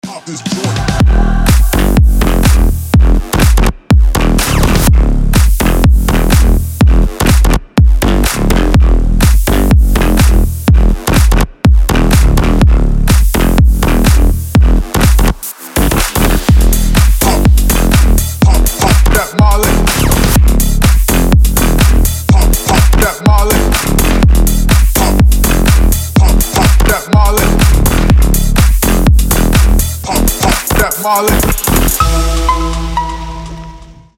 • Качество: 320, Stereo
EDM
мощные басы
Bass House
G-House
Стиль: bass house